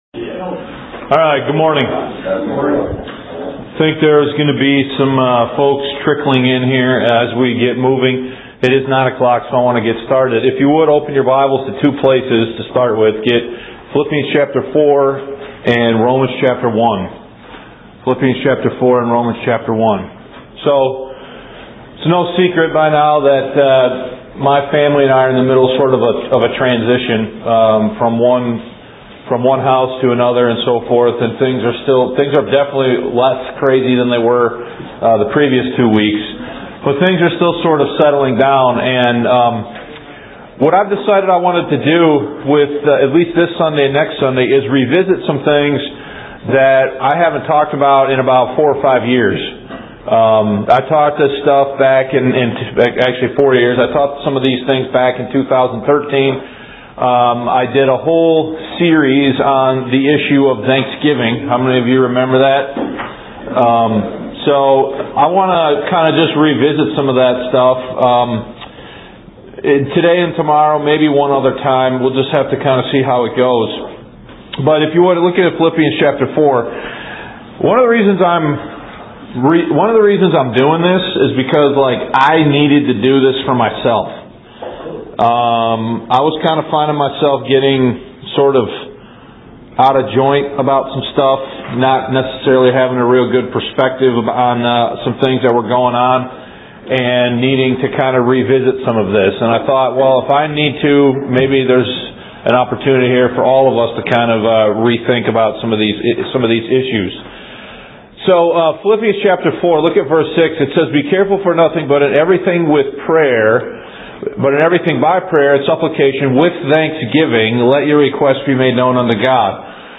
This one hour study is a reprisal and summation of two of those messages.